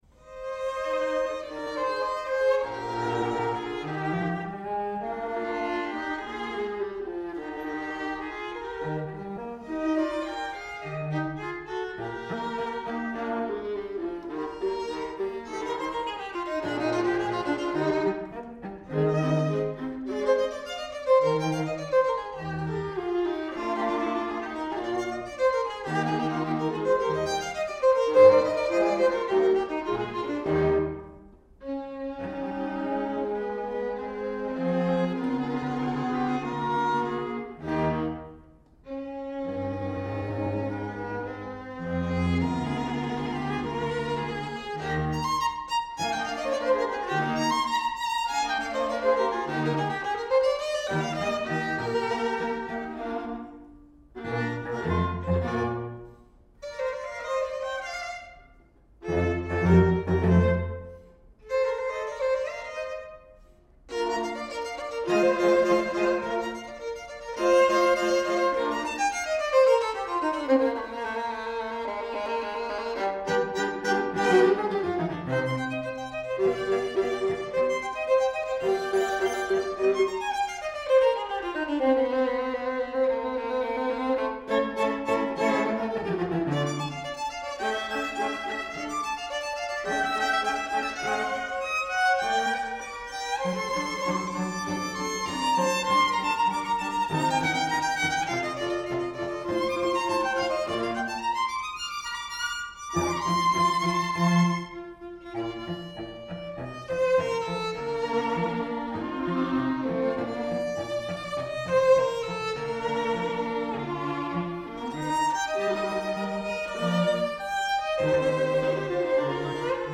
Soundbite 1st Movt